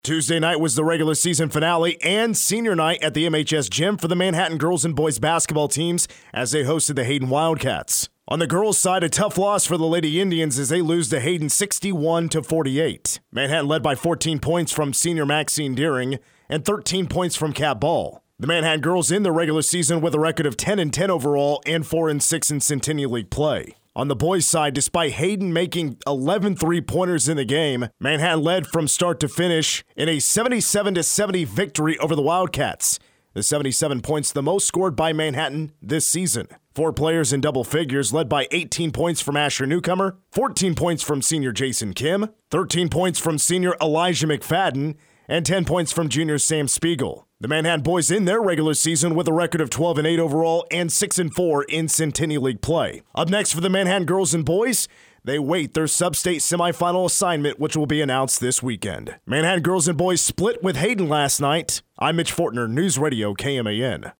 Recap